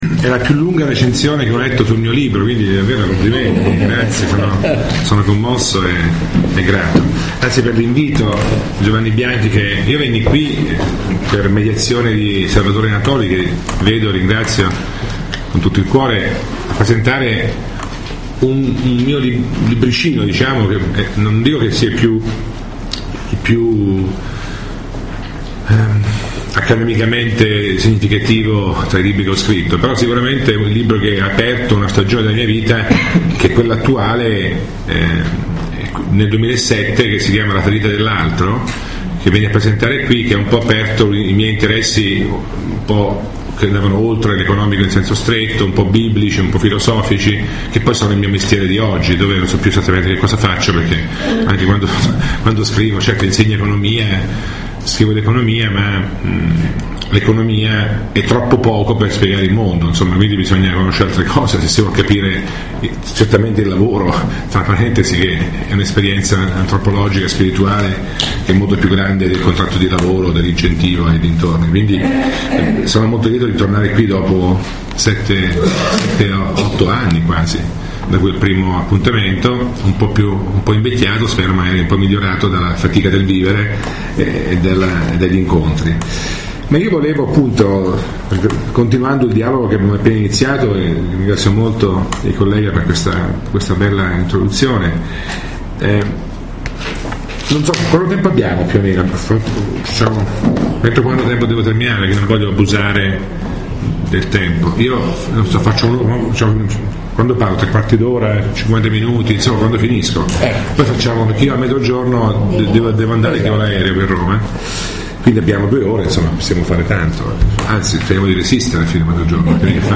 Fondati sul lavoro è la lezione che Luigino Bruni ha tenuto il 28 febbraio al Corso di Formazione alla politica 2014-2015 dei Circoli Dossetti di Milano.